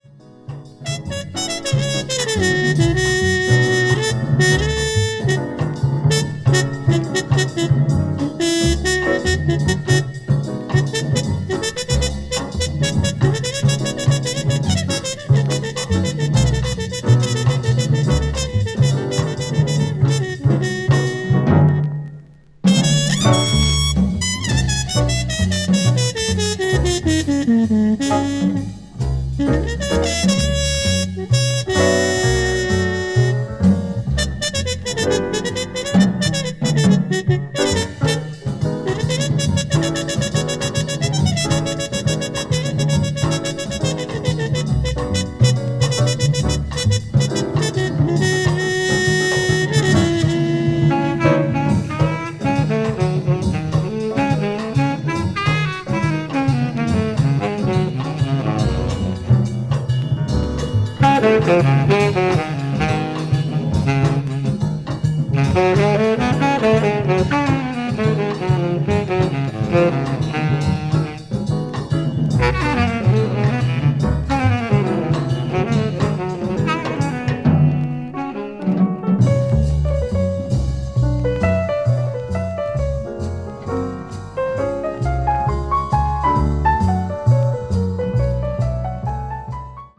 blues jazz   hard bop   modern jazz